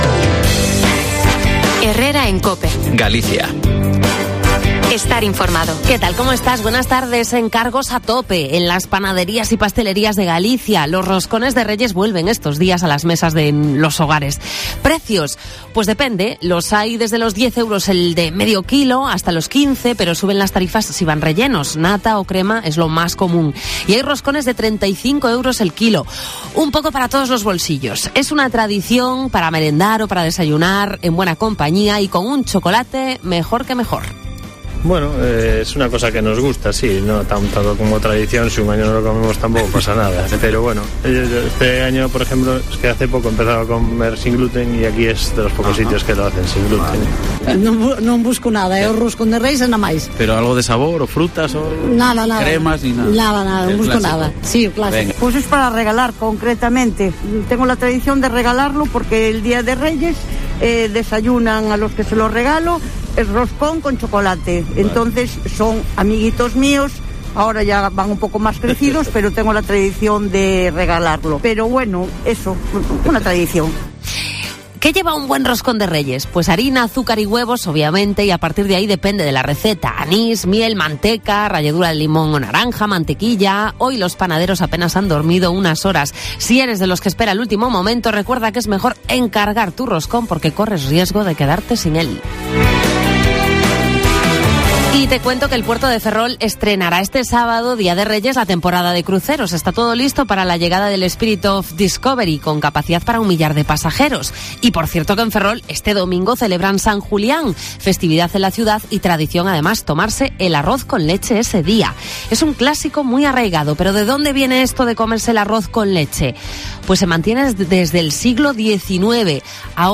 Escuchamos a algunos compradores de Roscones de Reyes: ¿con o sin relleno?